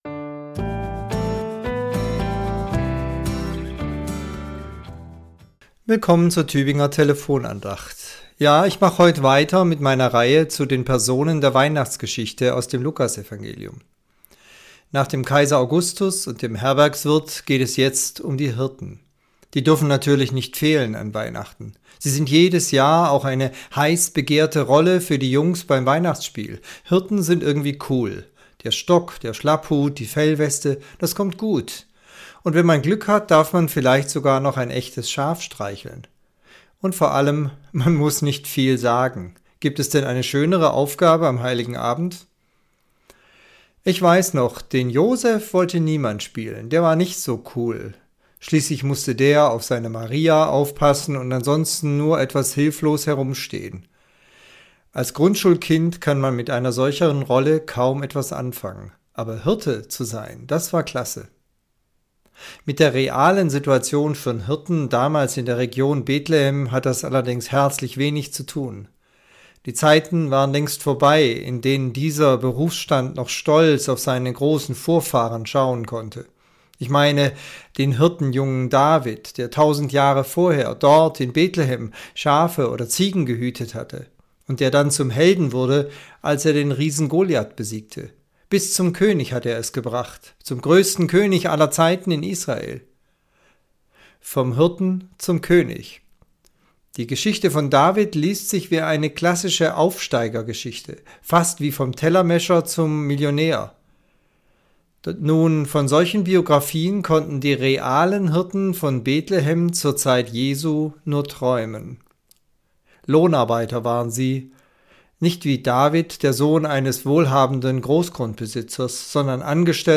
Andacht zur Weihnachtswoche Teil 3